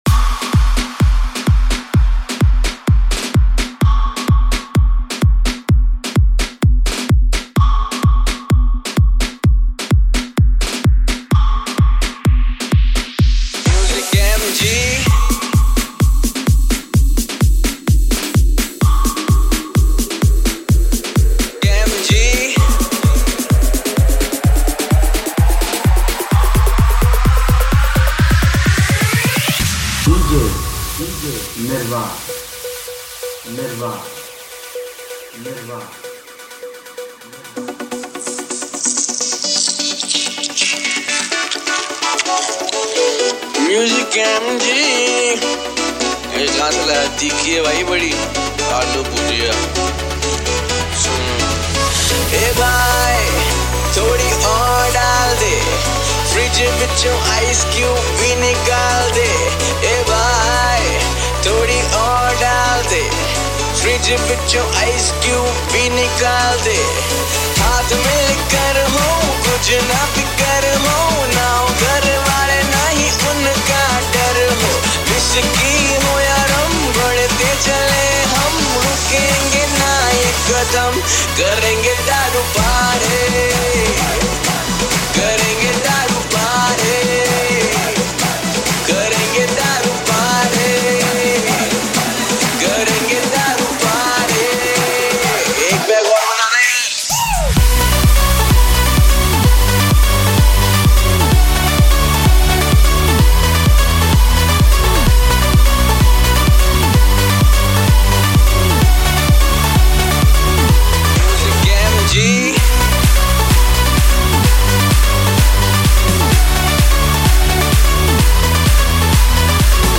DJ Remix Mp3 Songs